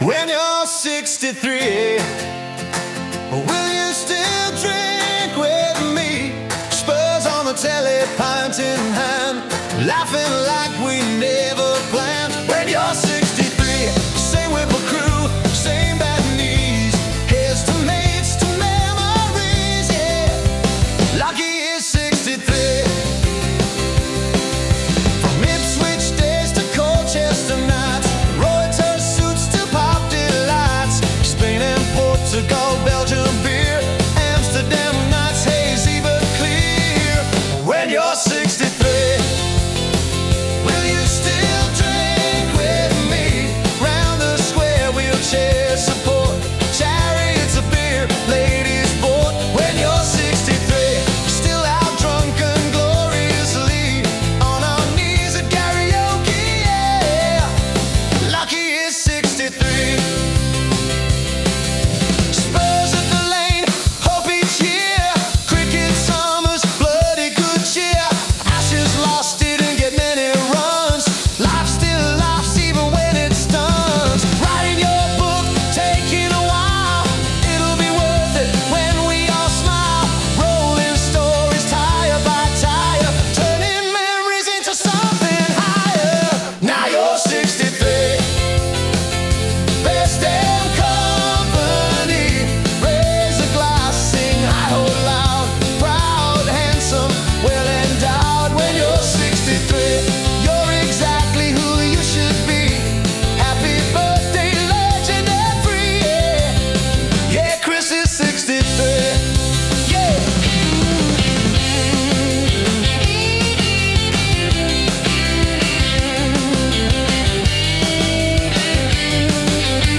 Music and vocals by Suno.